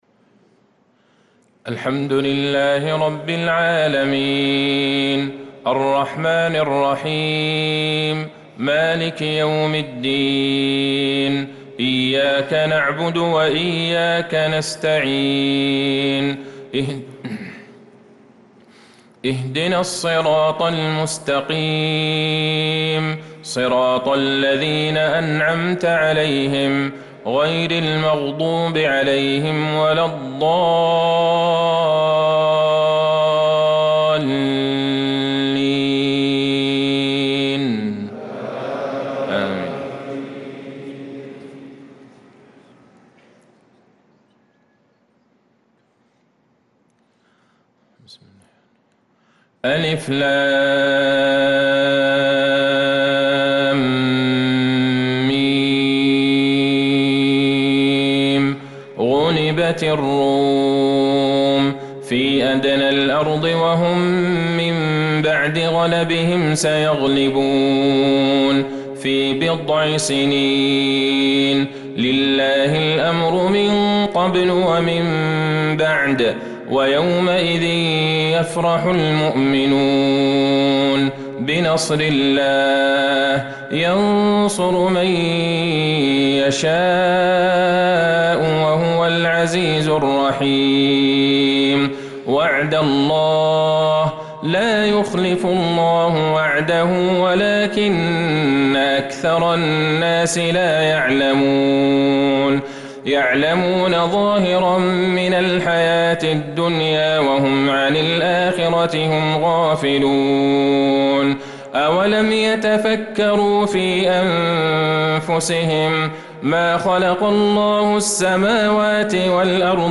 صلاة العشاء للقارئ عبدالله البعيجان 11 شوال 1445 هـ